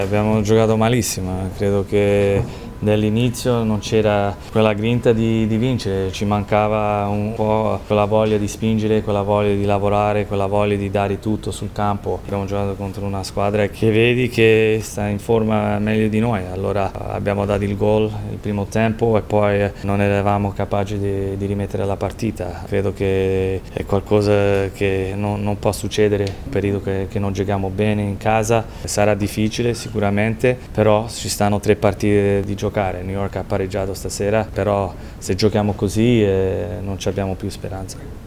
Le interviste post-partita: